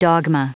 Prononciation du mot dogma en anglais (fichier audio)
Prononciation du mot : dogma